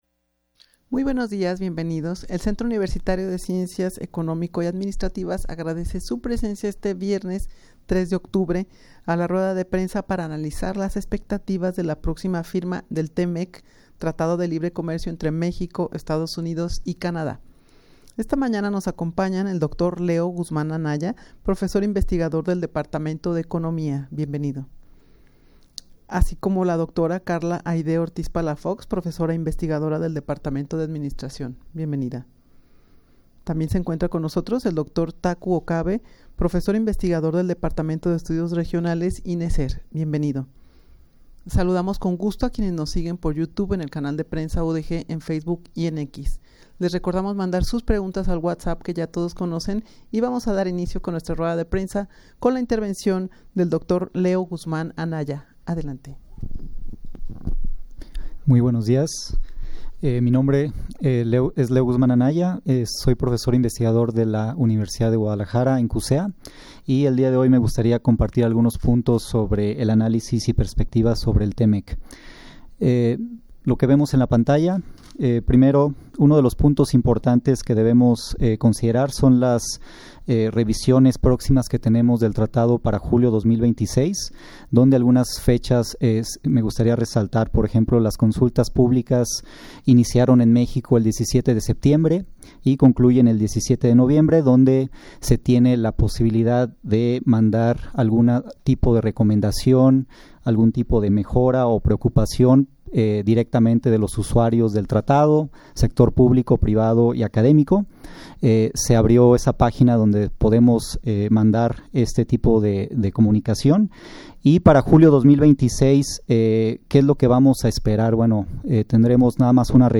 Audio de la Rueda de Prensa
rueda-de-prensa-para-analizar-las-expectativas-de-la-proxima-firma-del-t-mec.mp3